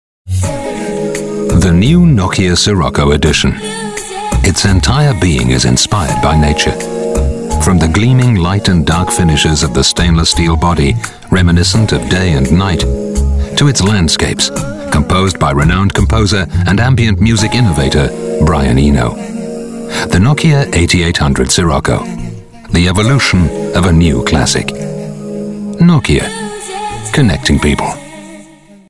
Sprecher englisch britisch.
Sprechprobe: Werbung (Muttersprache):
native english / british voice over talent. I am the warm voice of authority specializing in corporate videos, upmarket advertising, e-learning, documentary